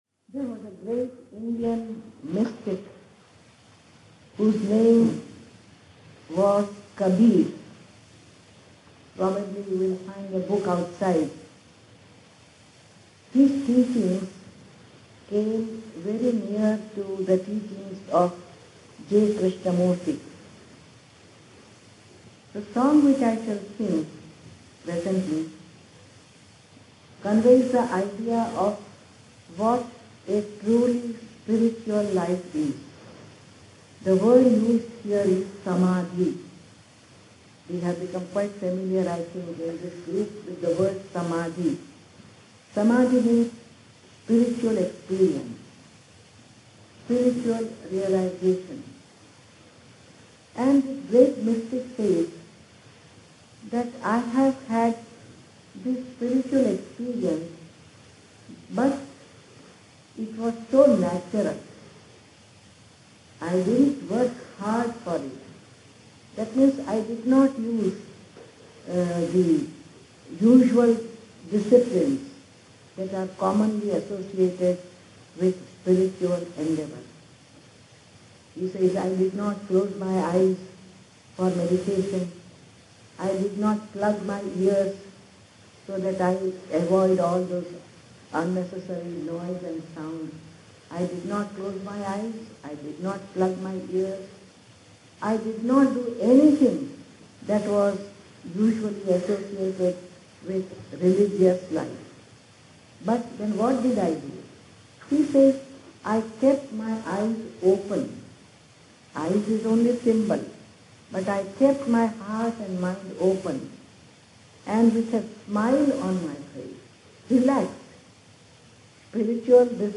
Below are audio recordings taken from a seminar he delivered in the Netherlands in June 1990.